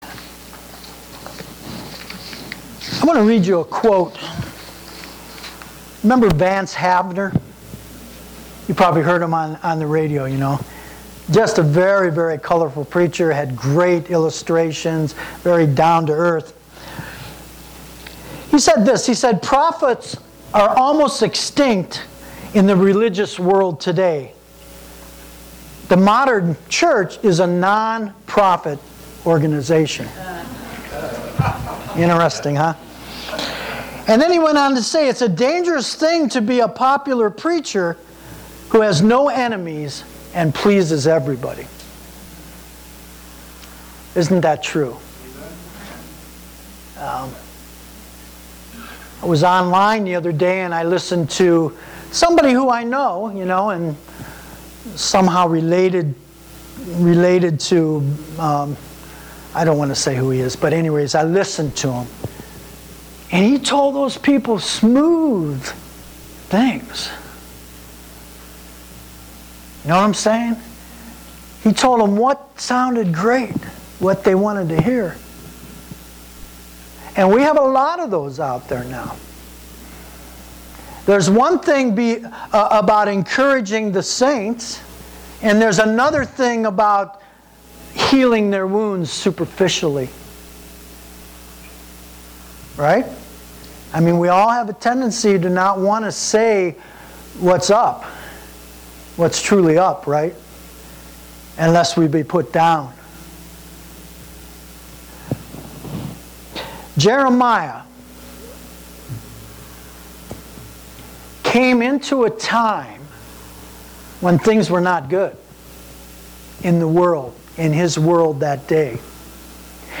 May 19, 2013 (Sunday Morning Service)